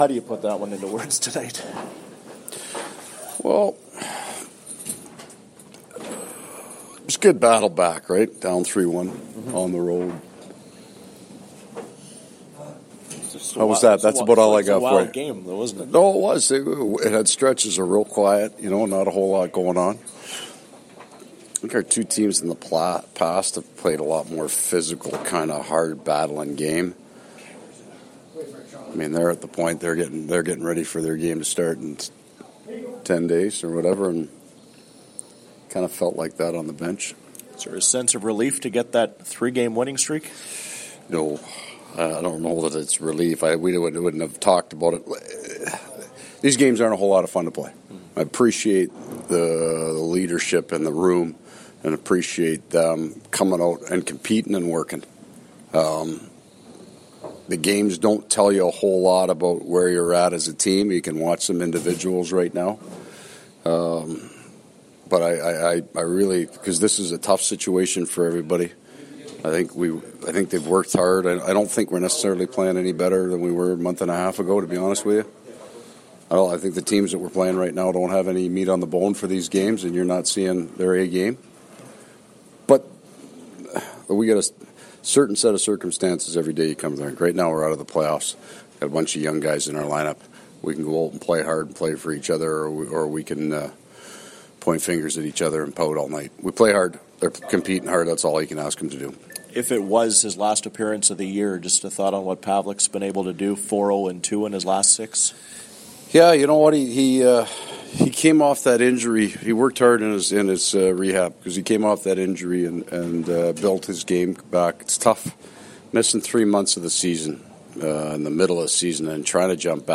Coach post-game scrum
Winnipeg Jets coach Paul Maurice post-game press conference.
Audio courtesy of TSN 1290 Winnipeg.